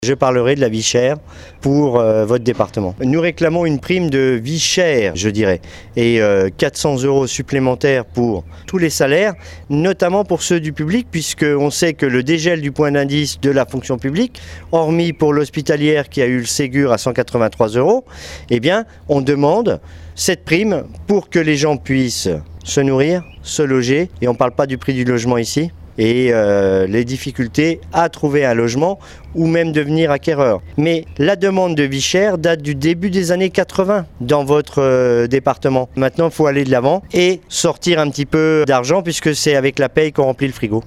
Il compte bien évoquer également avec la première ministre ce soir, la problématique du coût de la vie en Haute-Savoie. Il nous l’a confirmé au micro d’Ods Radio.